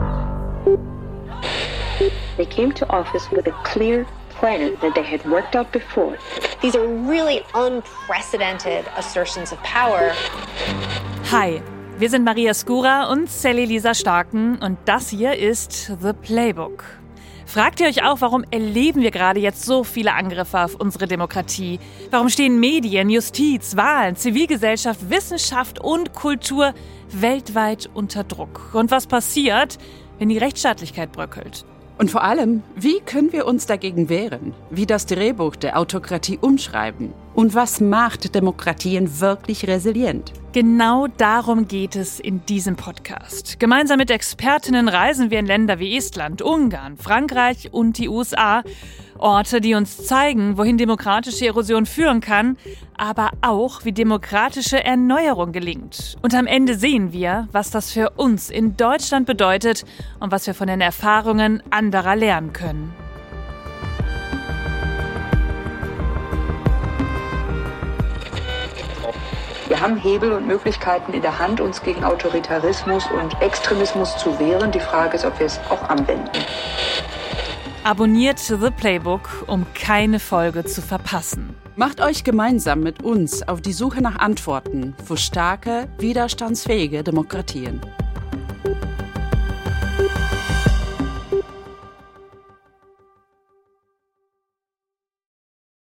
Trailer The Playbook – Demokratische Strategien gegen das Drehbuch der Autokraten